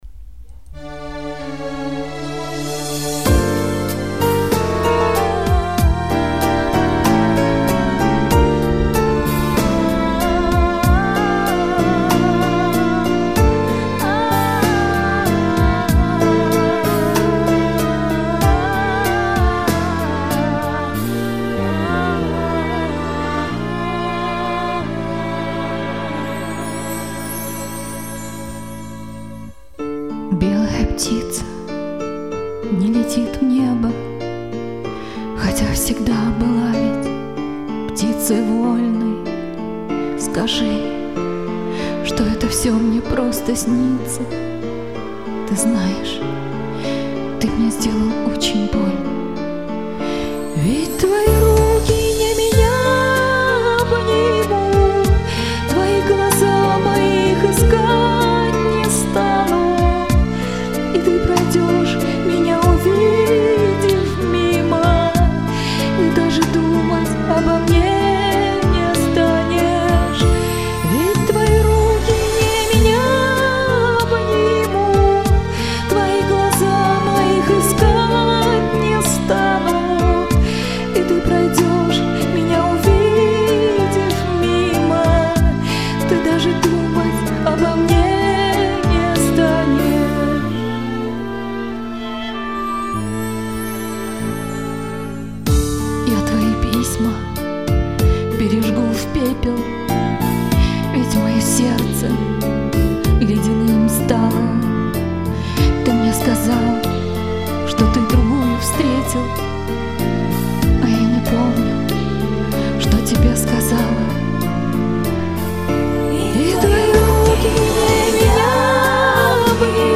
Она спела чище и приятней.
К вокалу претензий нет!!!buket_serdechki 2:3.